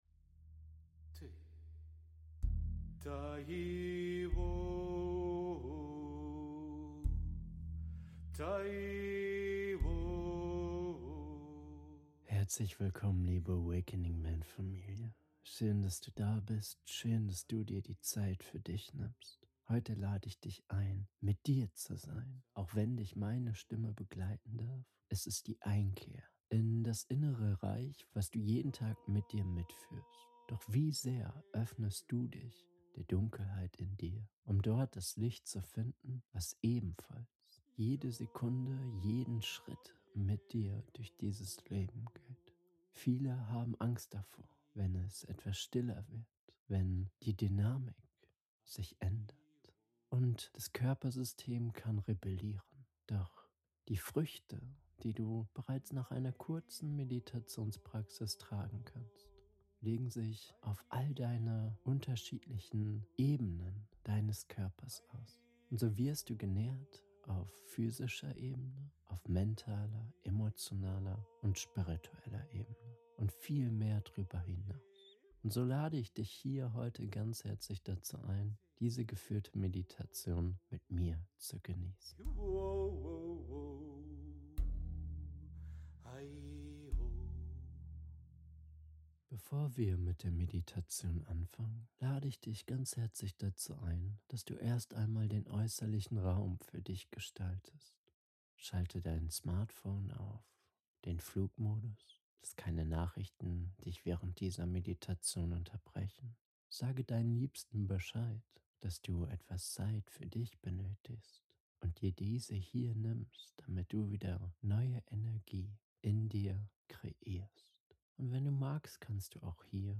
Geführte Meditation zur körperlichen Selbstannahme ~ AWAKENING MEN Podcast
Du wirst sanft aus dem Denken ins Spüren geführt. Deine Aufmerksamkeit sinkt in den Körper, in deine Füße, in deinen Bauch und wird dabei von deinen Atem getragen. Es geht um Präsenz.